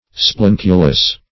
Search Result for " splenculus" : The Collaborative International Dictionary of English v.0.48: Splenculus \Splen"cu*lus\, n.; pl.